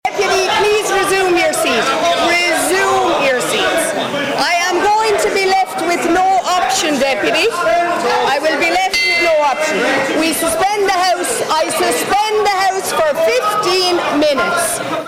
The Dáil has been suspended for a second time amid a shouting match between the Ceann Comahirle and opposition TDs.
New Ceann Comhairle, Verona Murphy, came in for sharp criticism and suspended the house: